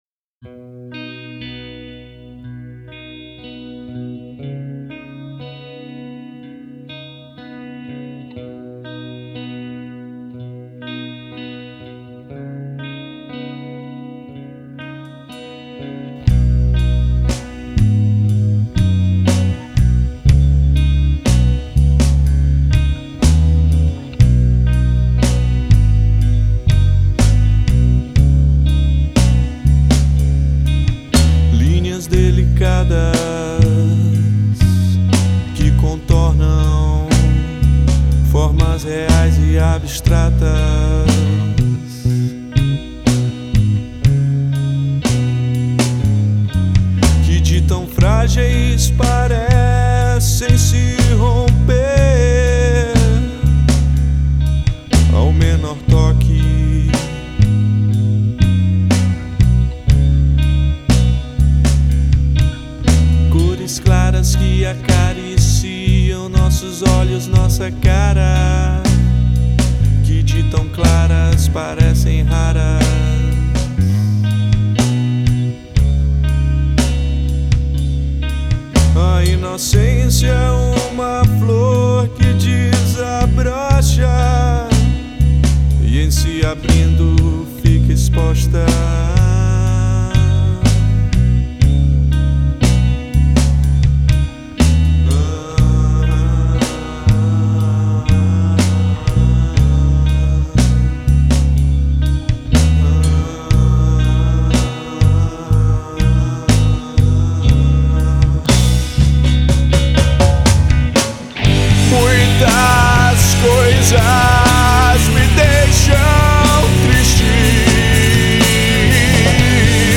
2711   05:19:00   Faixa: 6    Rock Nacional